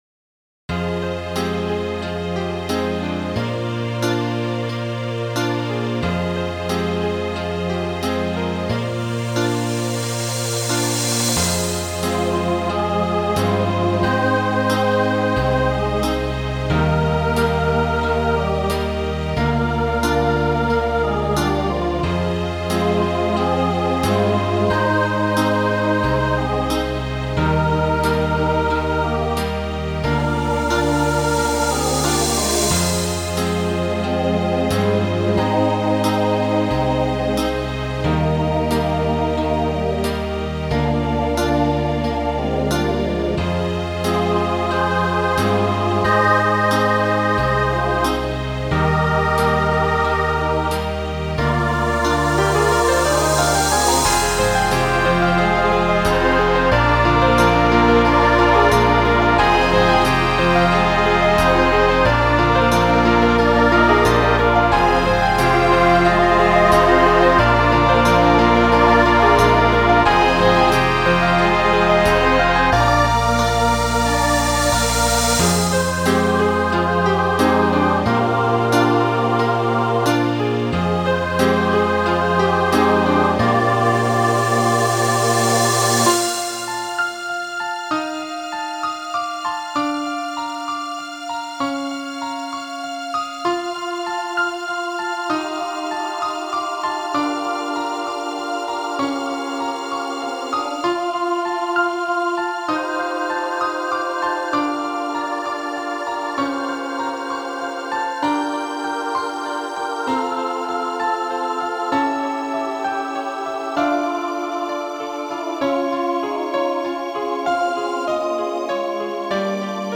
Voicing SATB Instrumental combo Genre Pop/Dance
2000s Show Function Ballad